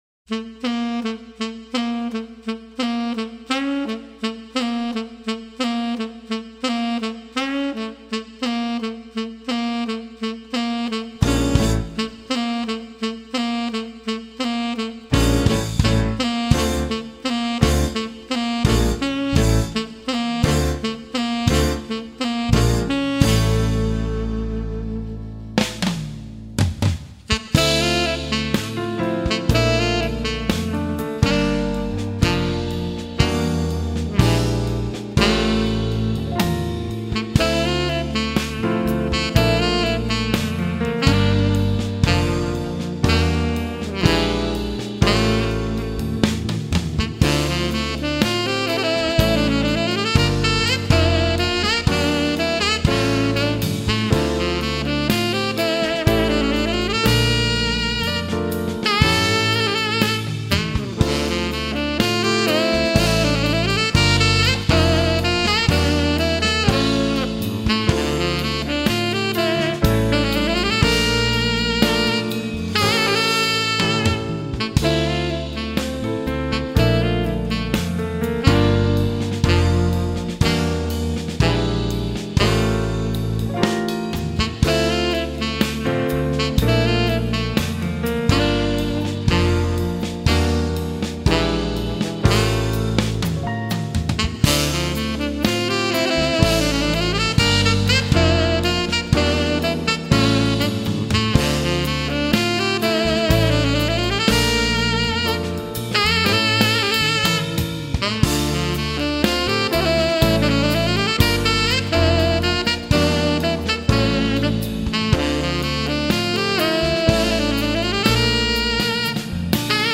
2019   04:34:00   Faixa:     Instrumental